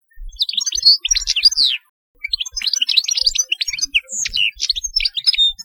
Chardonneret
Carduelis carduelis
chardonneret.mp3